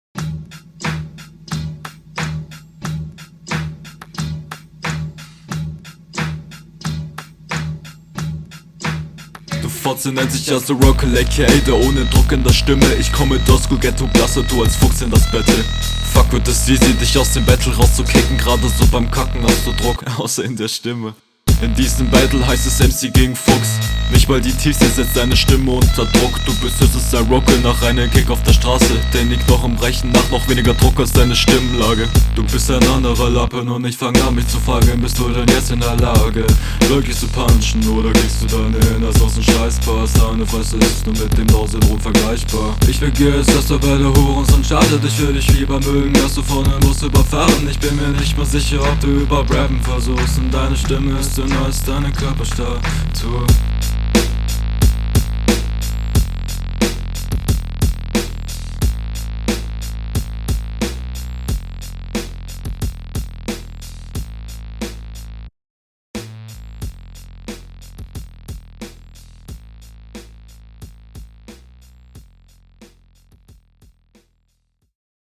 Klingt alles angenehm, der "Sing Sang" Flow in der zweiten Hälfte klingt cool und auch …